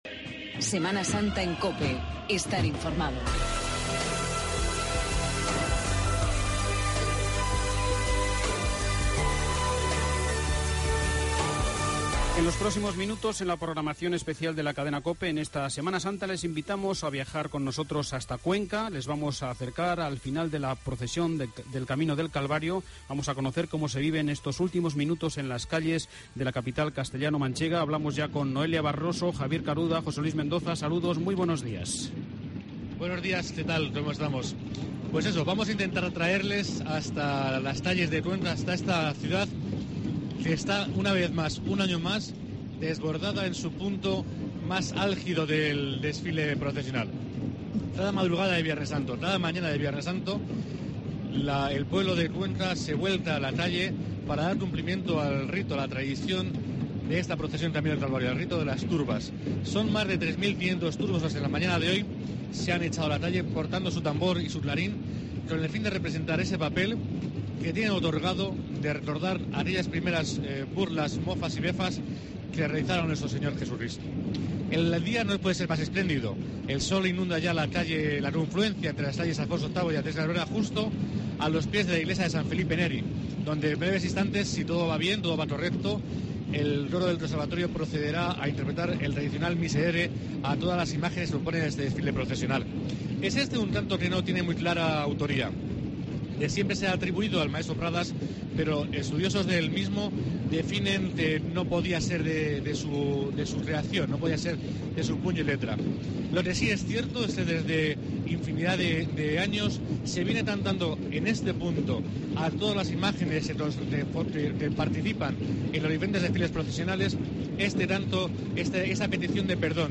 AUDIO: La Voz de la Pasión retransmisión nacional de la procesión Camino del Calvario y Miserere de 11.00 a 11.30 horas